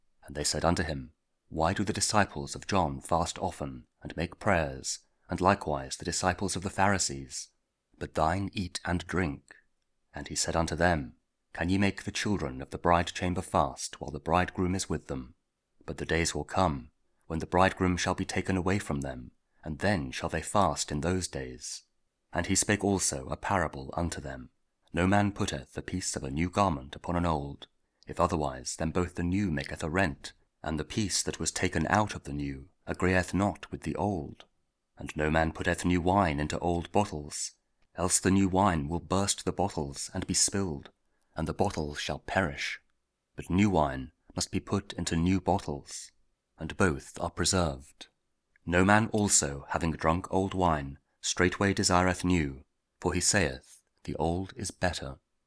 Audio Daily Bible